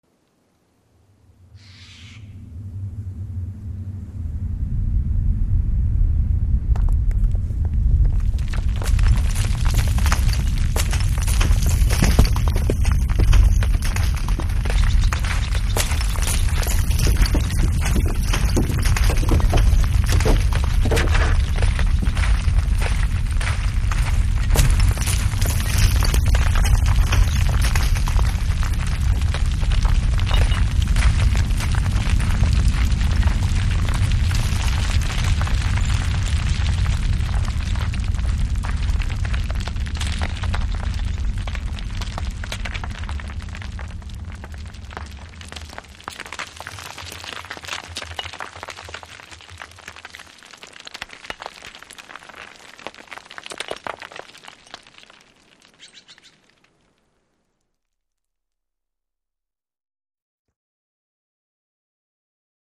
Heavy Rumble With Small And Larger Rocks Begin To Fall, Bird Calls In Distance.